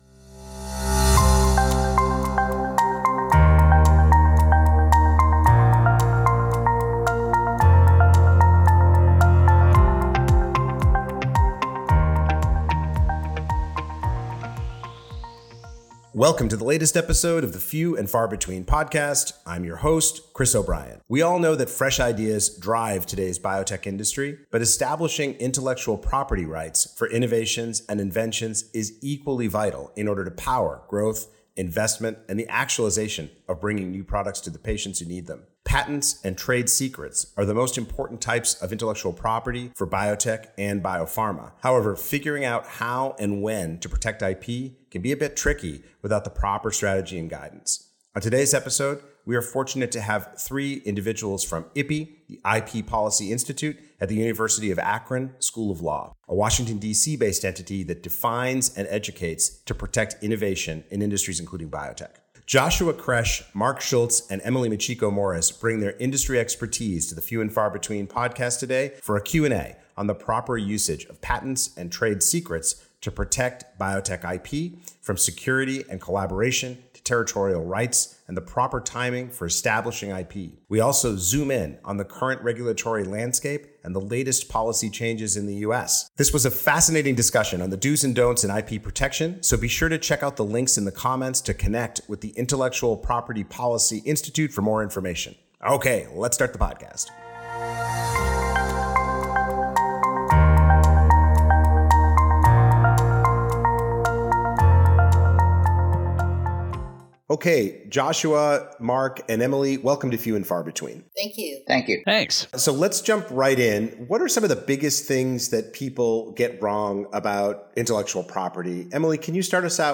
Tune in for a fascinating discussion on the dos and don'ts of IP protection.